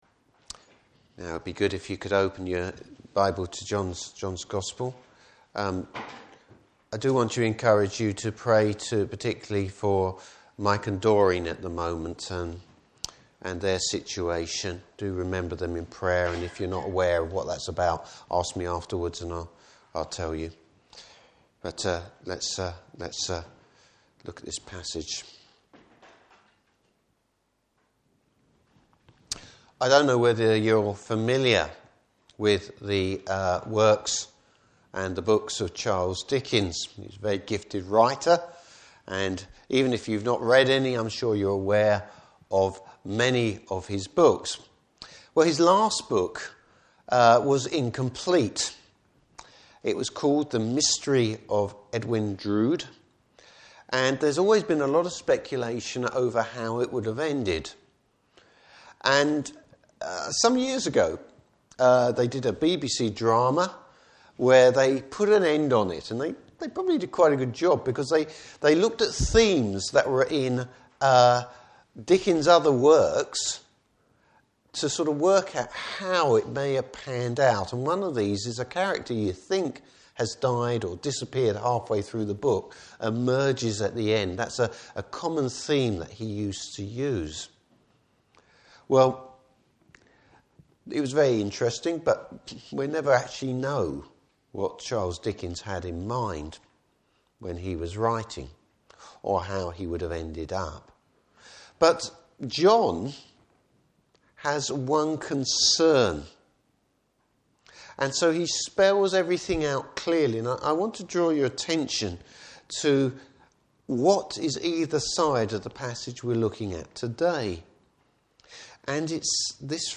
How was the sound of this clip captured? Service Type: Easter Day Morning Service.